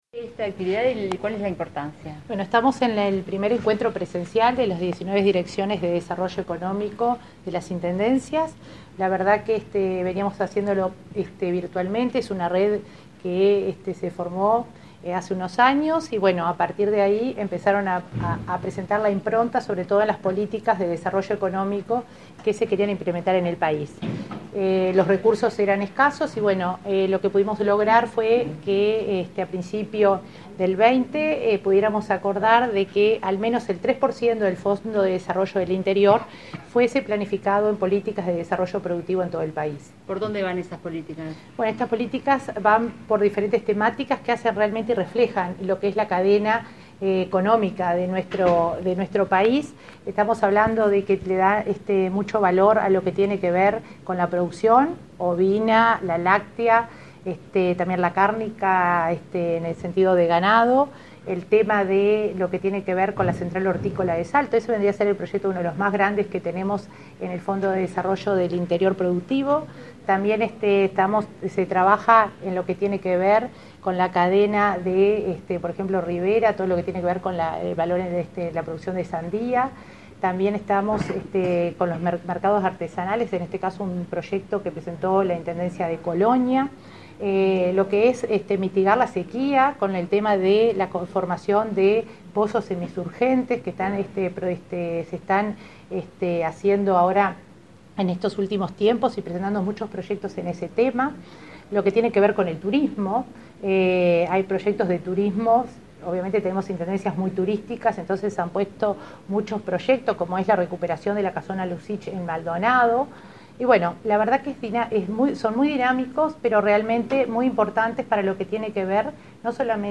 Declaraciones a la prensa de la coordinadora de Descentralización y Cohesión, María de Lima
Declaraciones a la prensa de la coordinadora de Descentralización y Cohesión, María de Lima 23/11/2021 Compartir Facebook X Copiar enlace WhatsApp LinkedIn Este 23 de noviembre se realizó el encuentro de los 19 directores de Desarrollo Económico de las intendencias. Tras la reunión, la coordinadora de Descentralización y Cohesión, María de Lima, efectuó declaraciones a la prensa.